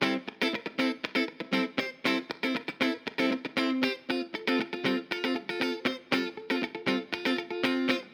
28 Guitar PT1.wav